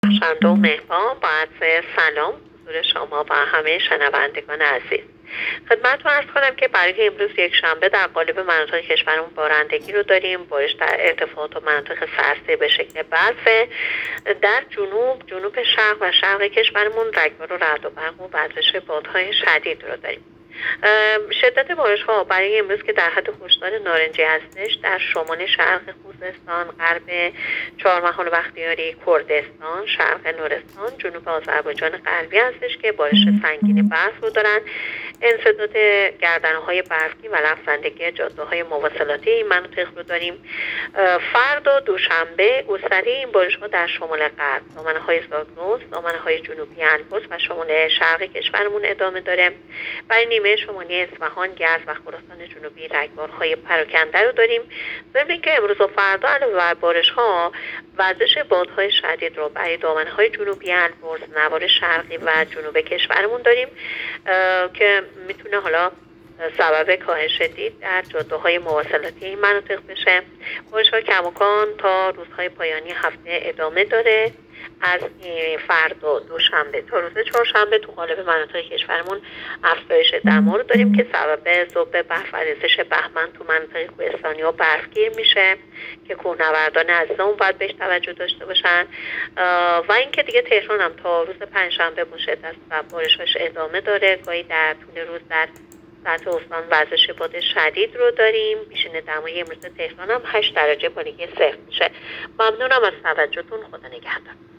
گزارش آخرین وضعیت جوی کشور را از رادیو اینترنتی پایگاه خبری وزارت راه و شهرسازی بشنوید.
گزارش رادیو اینترنتی از آخرین وضعیت آب و هوای نوزدهم بهمن؛